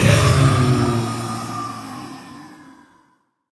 Minecraft Version Minecraft Version snapshot Latest Release | Latest Snapshot snapshot / assets / minecraft / sounds / mob / blaze / death.ogg Compare With Compare With Latest Release | Latest Snapshot